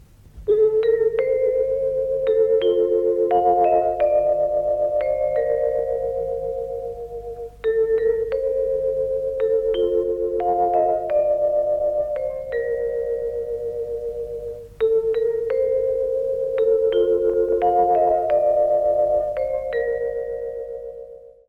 Sintonia de la cadena